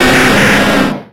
Cri de Rhinocorne dans Pokémon X et Y.